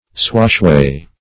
Swashway \Swash"way`\, n.